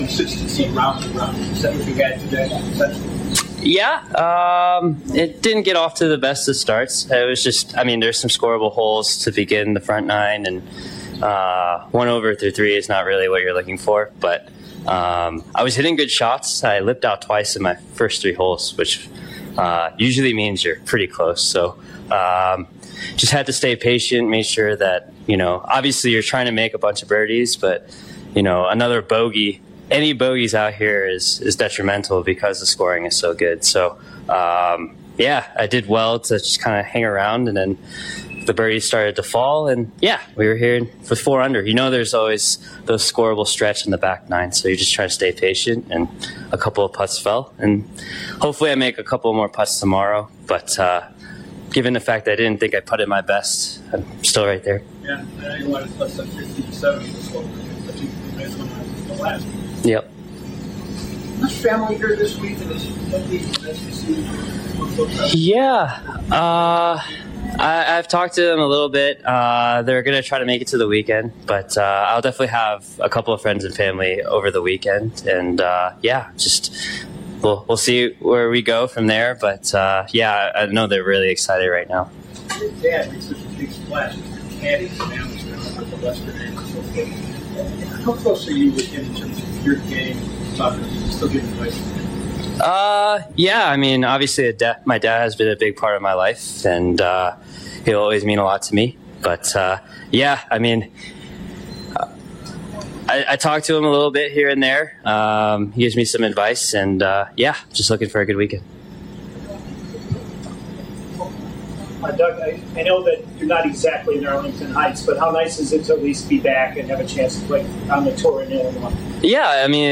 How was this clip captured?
at the JDC